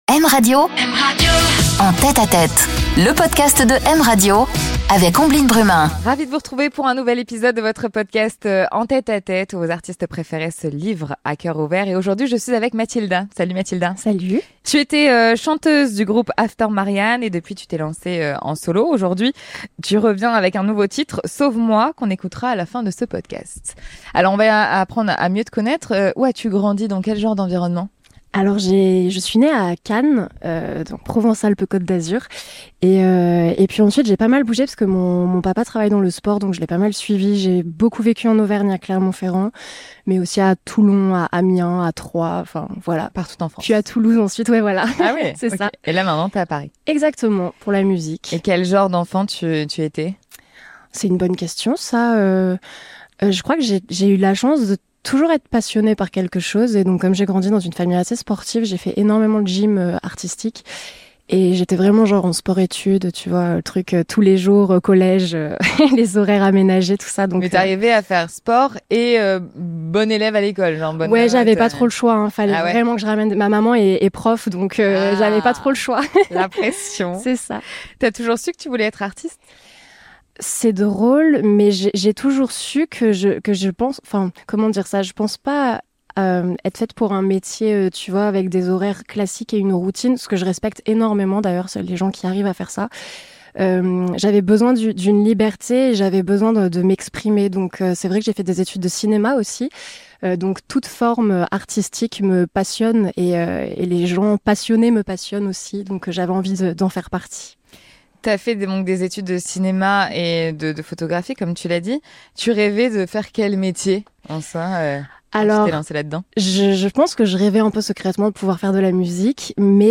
Une interview en confidence, dans l'intimité des artistes